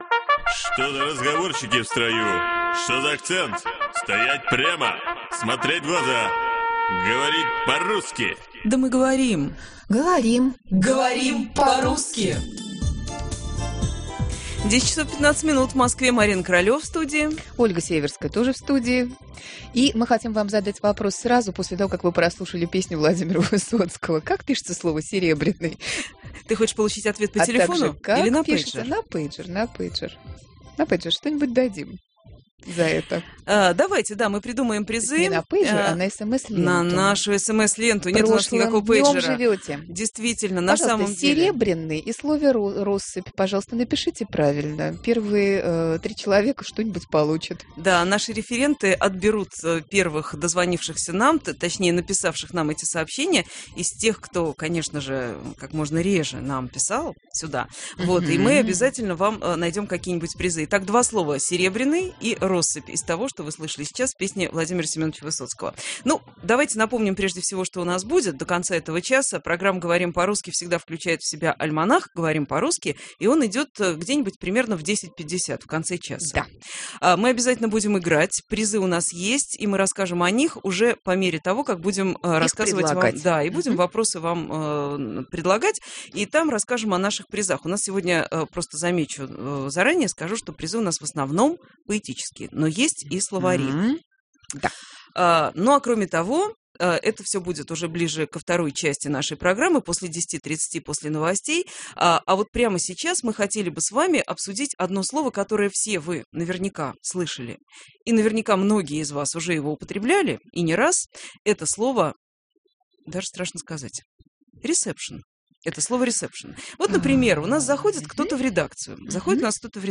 Говорим по-русски. Передача-игра